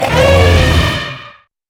hurt3.wav